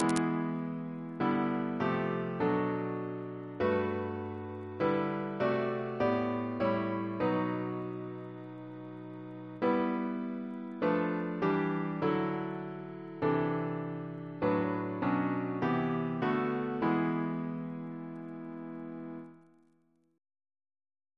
Double chant in E minor Composer: Daniel Pinkham (1923-2006), Organist of King's Chapel, Boston Reference psalters: ACP: 262; H1940: 791; H1982: S265